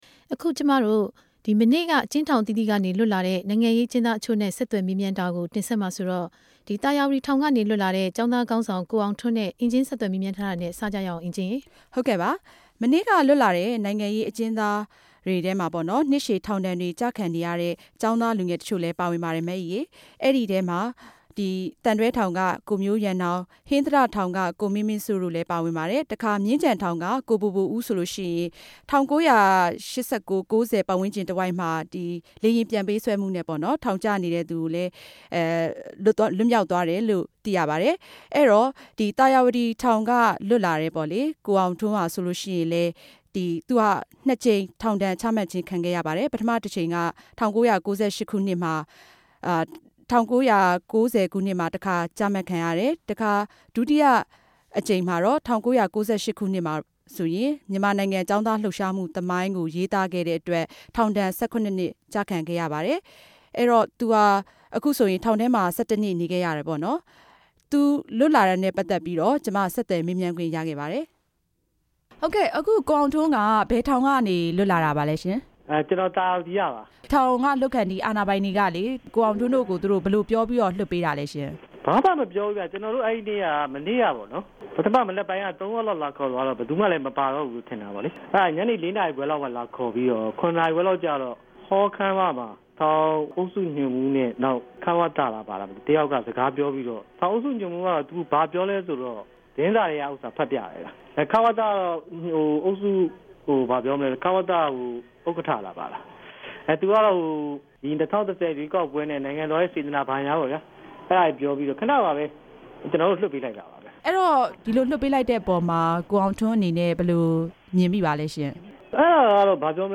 မနေႛက အကဵဉ်းထောင်အသီးသီးက လြတ်လာတဲ့ ိံိုင်ငံရေးအကဵဉ်းသားတခဵိြႛကို RFA က ဆက်သြယ်မေးူမန်းခဲ့ပၝတယ်။